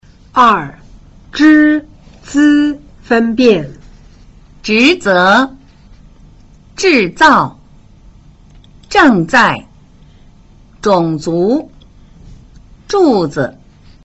1. 平舌音 z c s和 捲舌音 zh ch sh 的比較﹕
2） zh – z分辨